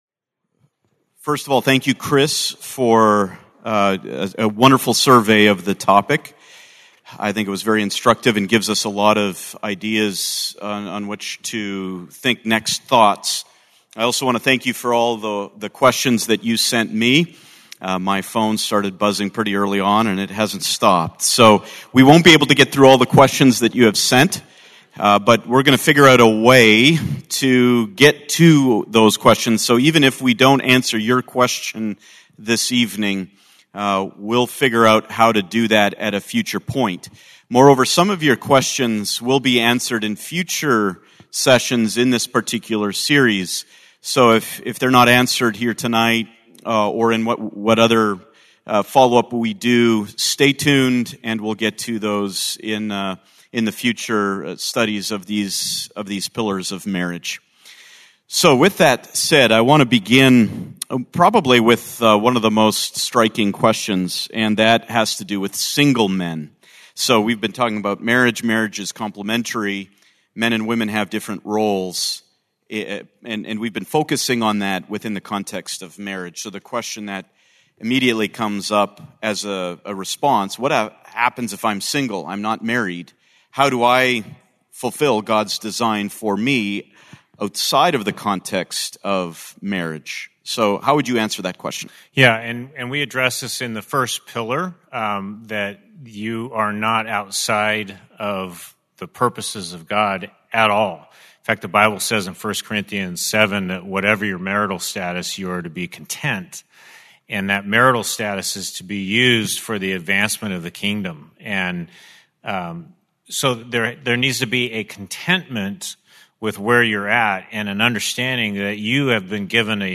The Pillars of Marriage: Panel Discussion, Part 2